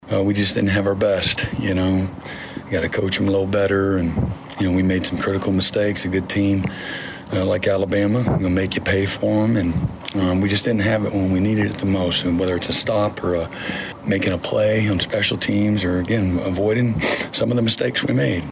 Here is Sooners Coach Brent Venables
postgame following the loss.